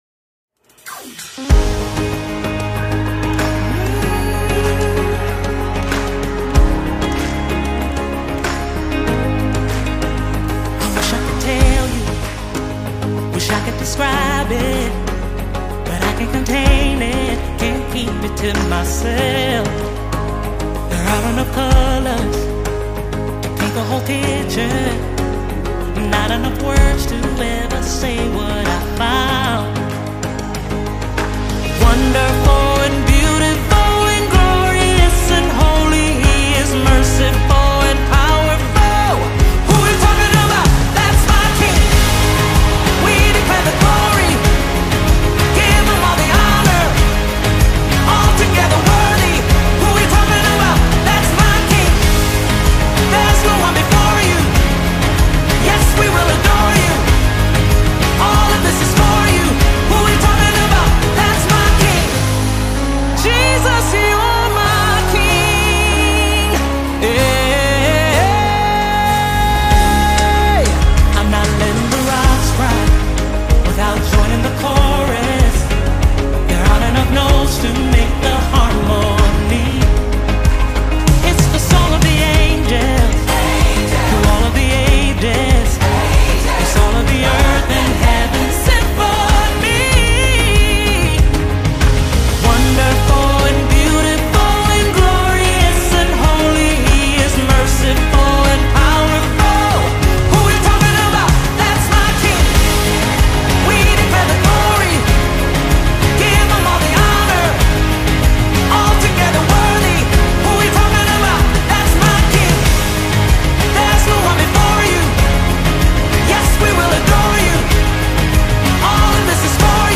exuberant and uplifting gospel anthem
blends heartfelt worship with a joyful, rhythmic groove
live album
American Gospel Songs